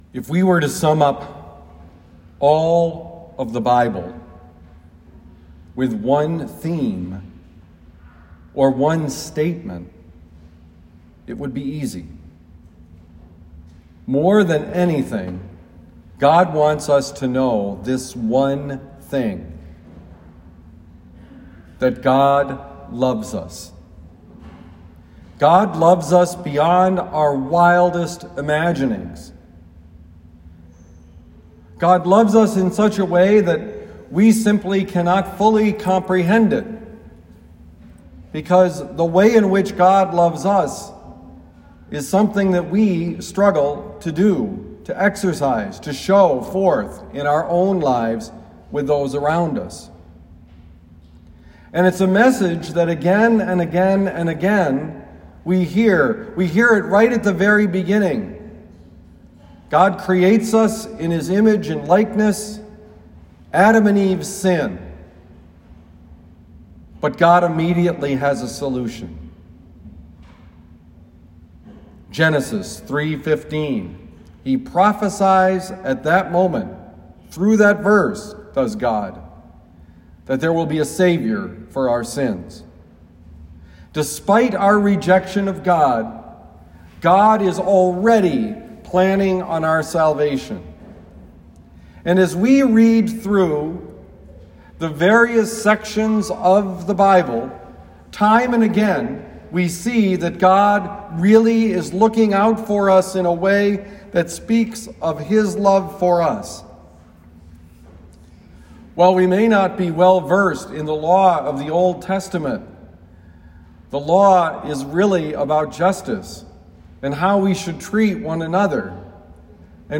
Homily for Palm Sunday, March 28, 2021
Given at Our Lady of Lourdes Parish, University City, Missouri.